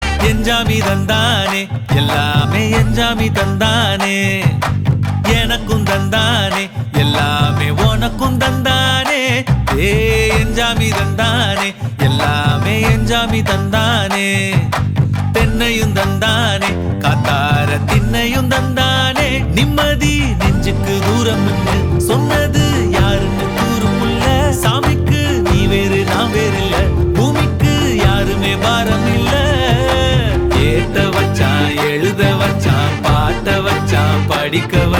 bgm ringtone